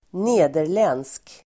Ladda ner uttalet
nederländsk adjektiv, Dutch Uttal: [²n'e:der_len:(d)sk] Böjningar: nederländskt, nederländska Synonymer: holländsk Definition: som är från el. avser Nederländerna (Holland), holländsk nederländska , Dutch